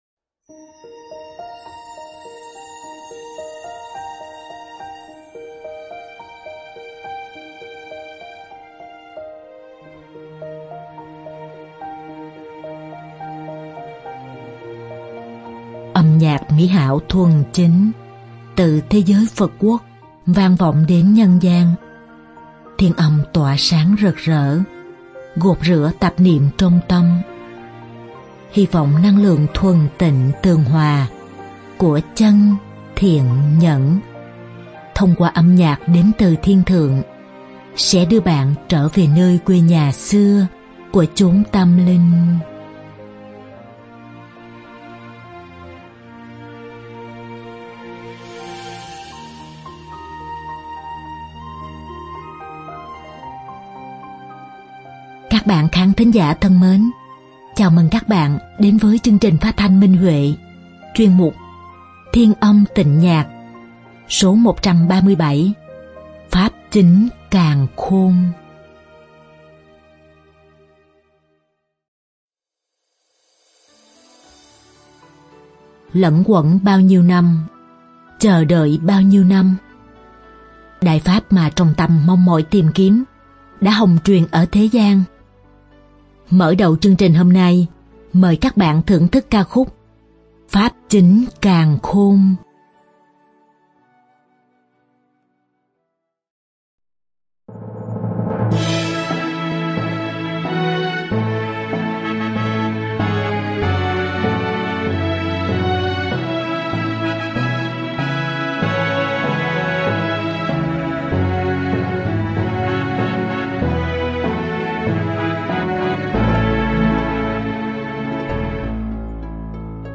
Âm nhạc mỹ hảo thuần chính, từ thế giới Phật quốc vang vọng đến nhân gian, thiên âm tỏa sáng rực rỡ, gột rửa tạp niệm trong tâm, hy vọng năng lượng thuần tịnh